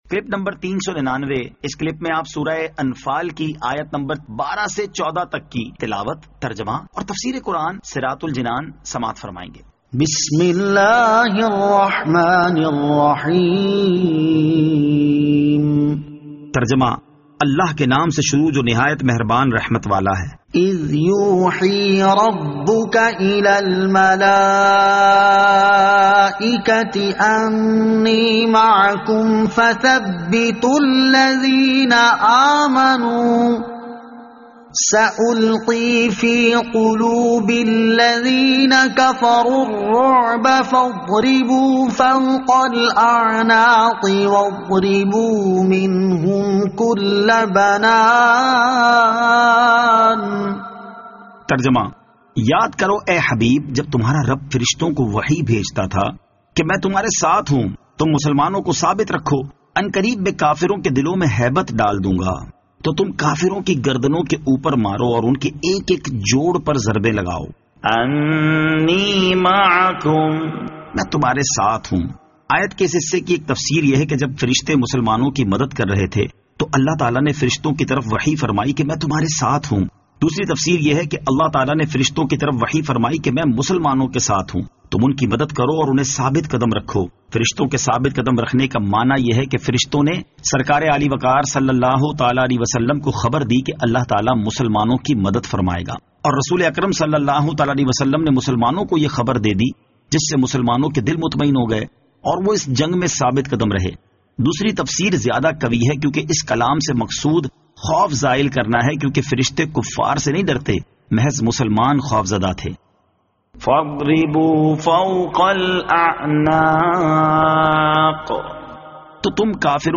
Surah Al-Anfal Ayat 12 To 14 Tilawat , Tarjama , Tafseer